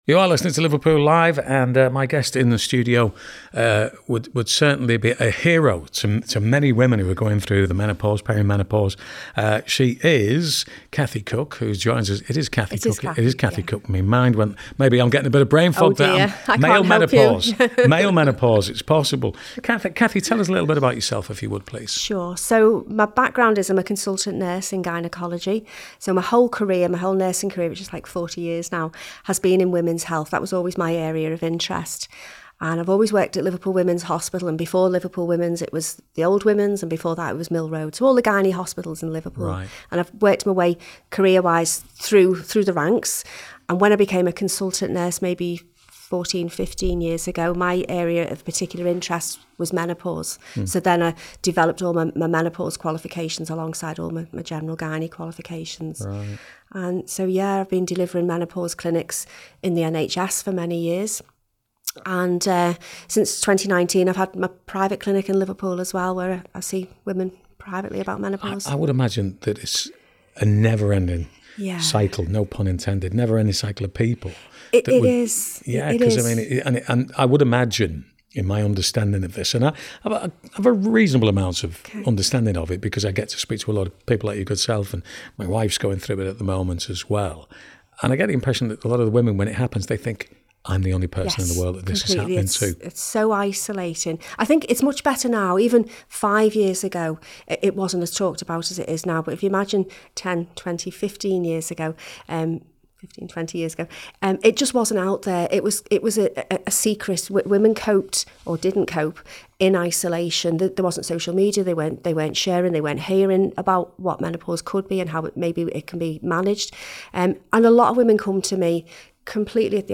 Liverpool Live Interview